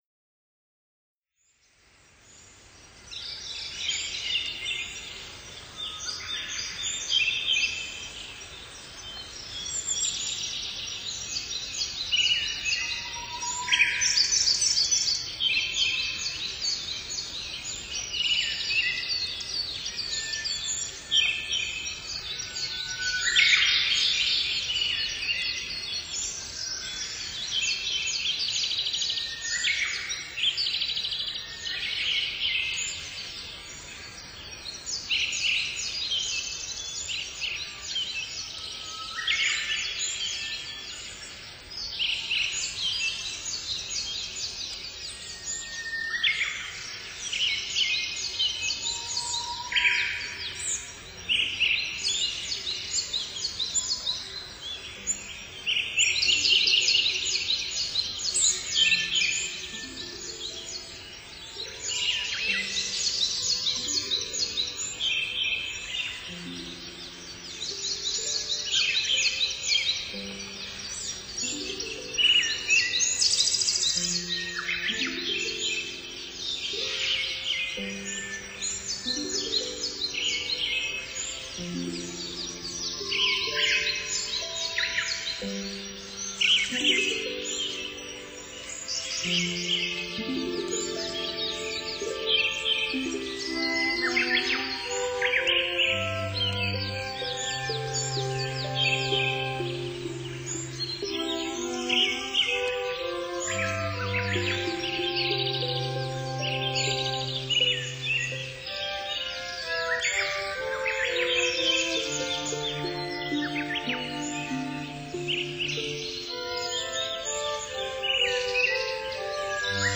Ambient Project